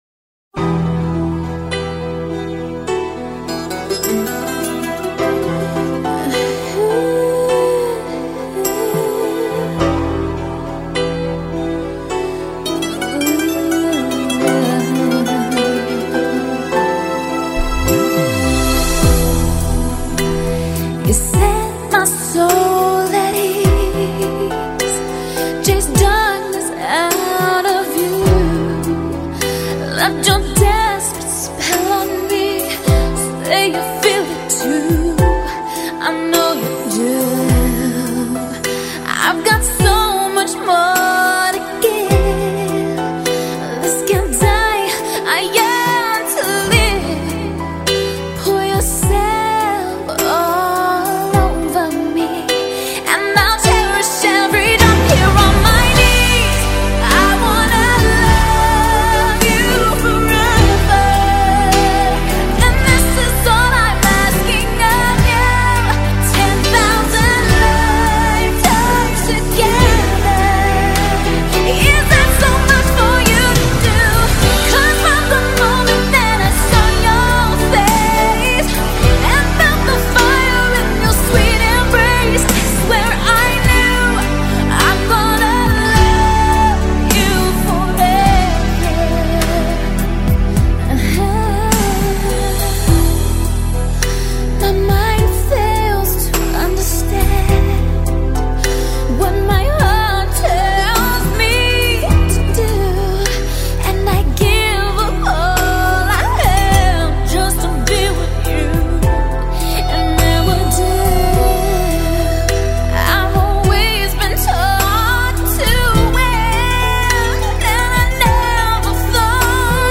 1999年底以高亢清亮的嗓音演唱